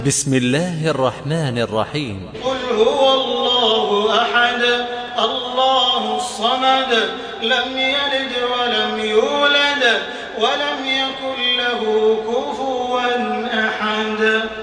تراويح الحرم المكي 1428
مرتل